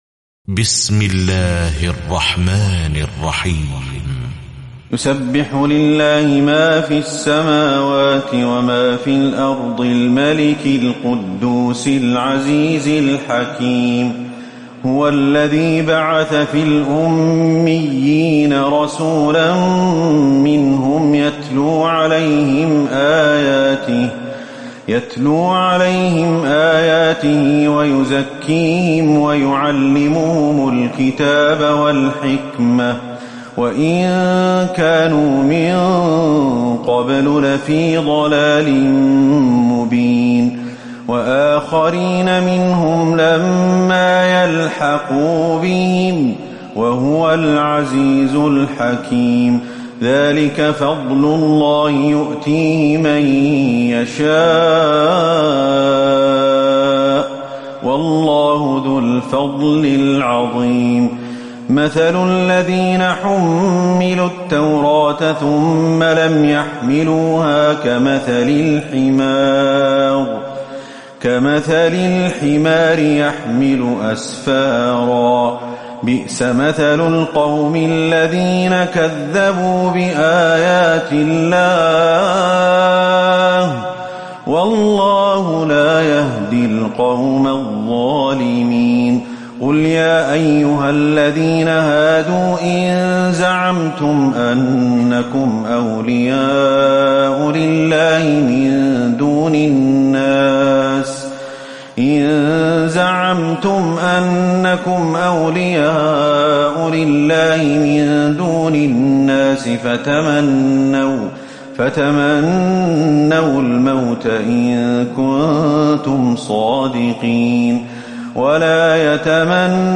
تراويح ليلة 27 رمضان 1438هـ من سورة الجمعة الى التحريم Taraweeh 27 st night Ramadan 1438H from Surah Al-Jumu'a to At-Tahrim > تراويح الحرم النبوي عام 1438 🕌 > التراويح - تلاوات الحرمين